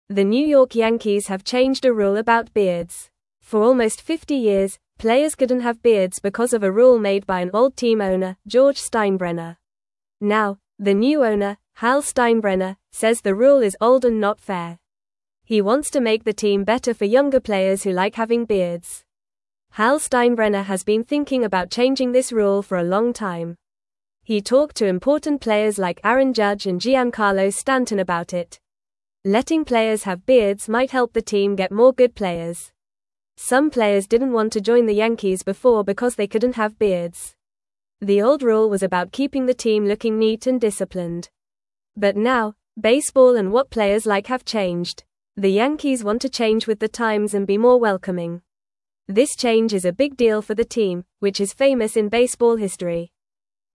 Fast
English-Newsroom-Lower-Intermediate-FAST-Reading-Yankees-Players-Can-Now-Have-Beards-Again.mp3